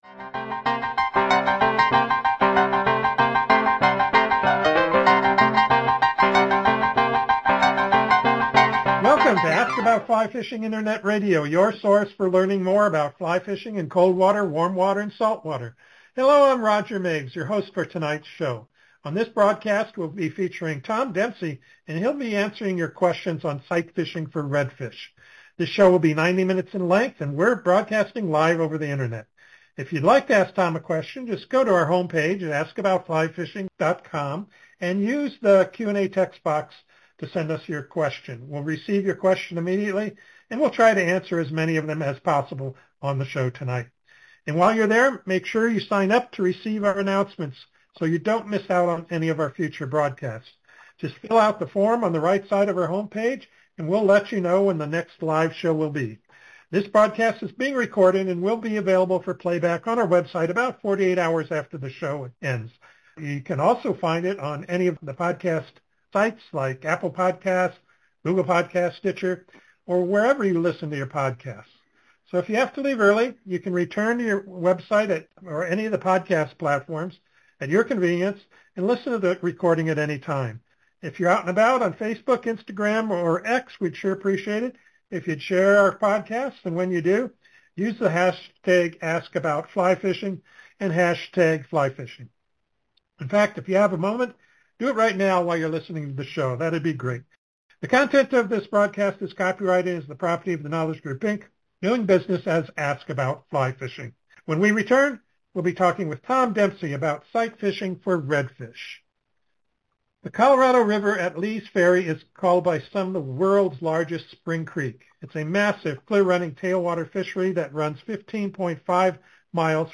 Just a few of the questions asked and answered during the interview: How did you get started fly fishing for redfish?